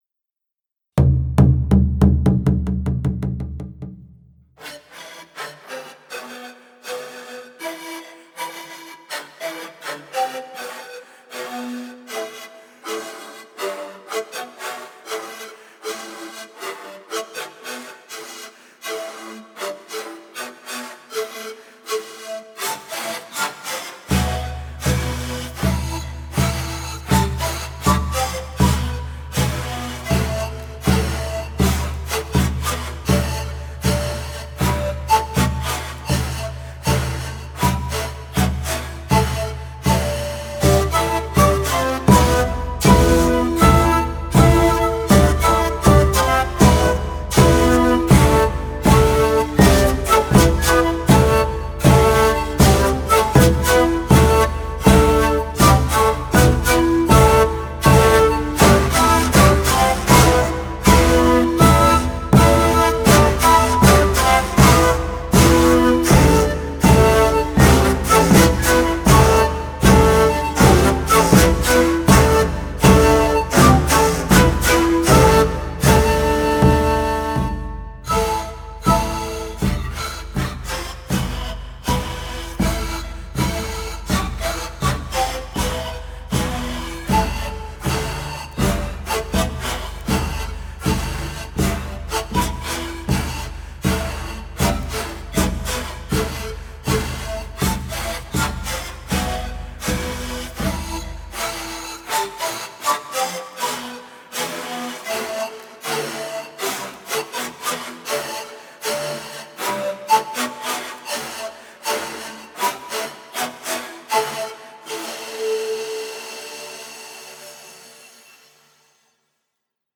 Pillco Canto Ritual (Bolivia), Los Folkloristas (2014)
pillco-canto-ritual-bolivia.mp3